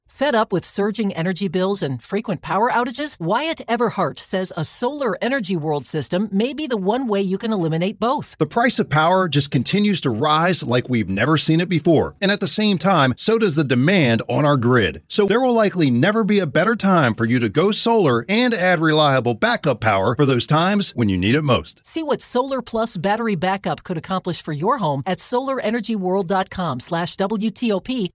is answering some of the most pressing questions about shifting to solar power in 2025 in the new 5-part WTOP interview series below.